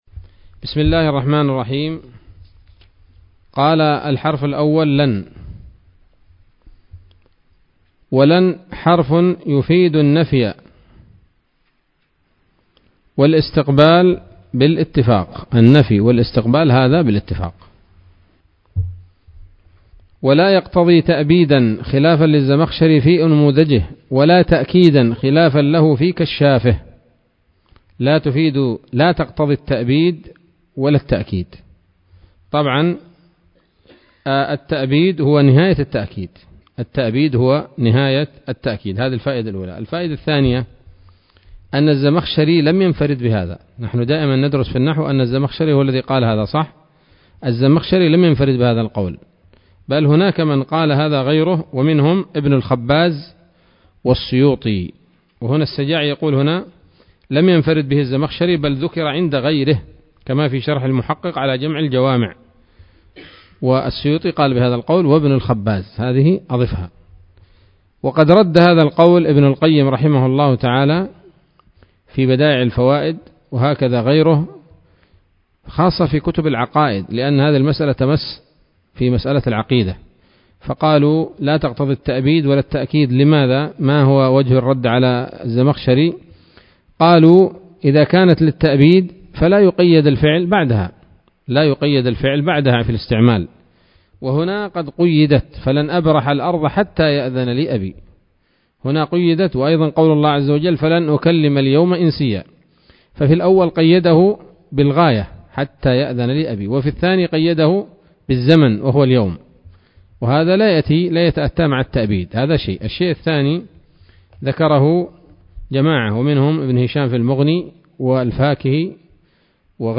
الدرس السادس والعشرون من شرح قطر الندى وبل الصدى [1444هـ]